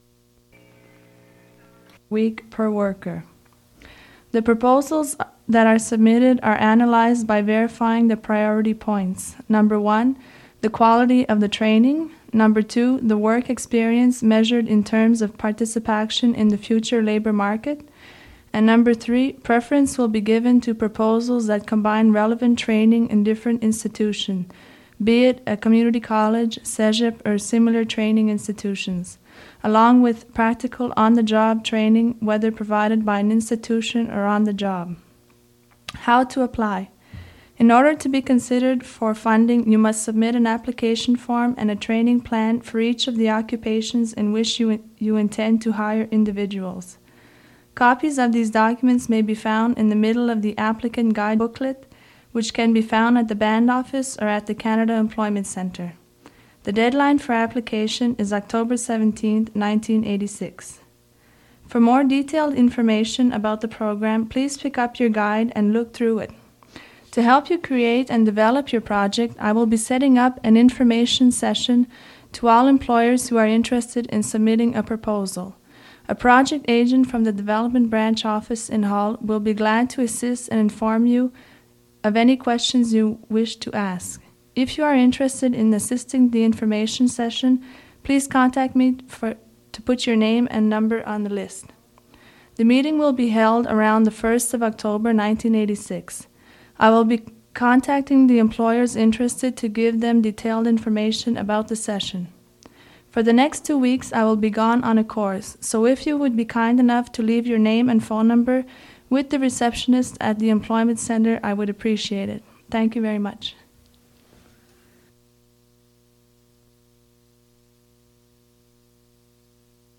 Fait partie de A professional development program announcement and acoustic reinterpretation of rock music